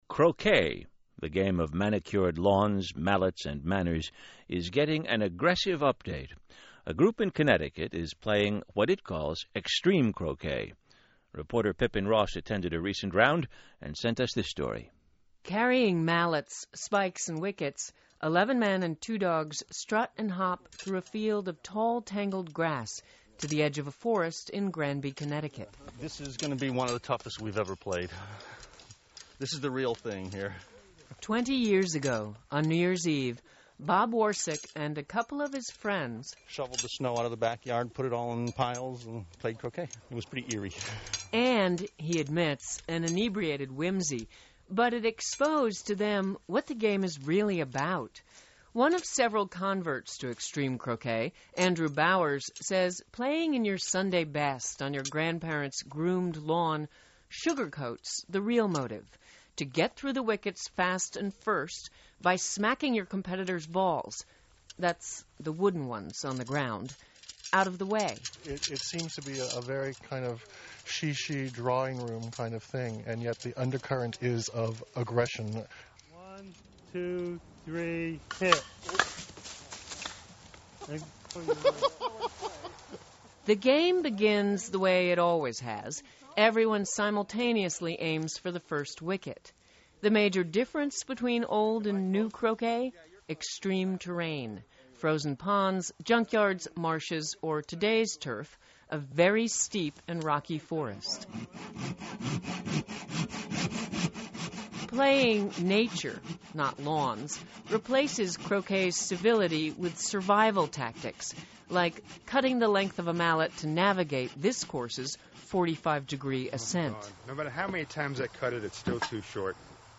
In September of 2004, National Public Radio (NPR) - did a radio article about us, and eXtreme Croquet, in Granby, Connecticut.
The feature was aired on NPR as part of their "All Things Considered" series.